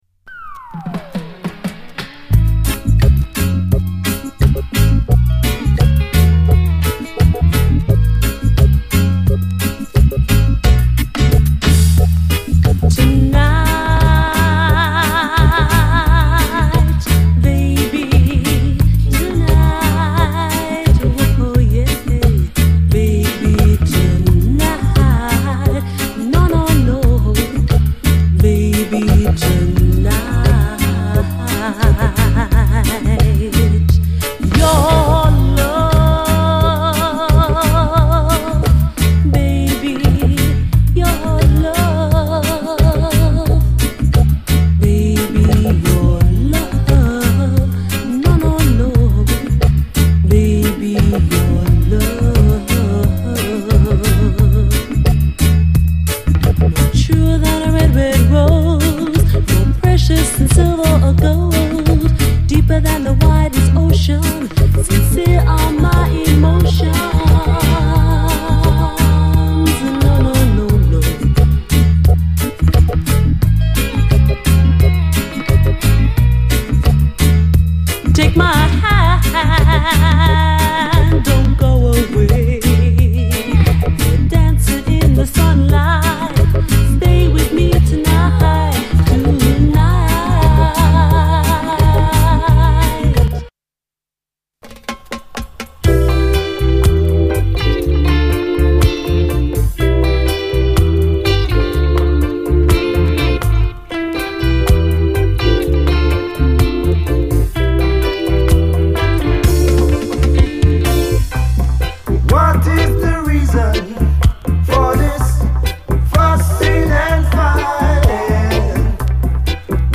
REGGAE
両面よい、男女混成UKラヴァーズ〜UKルーツ！
甘いメロディーでありつつも男っぽく骨のある曲で、個人的にはこちらの方が好みです。